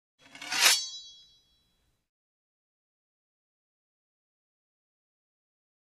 Sword Shing 3; Sword Edges Run Against Each Other.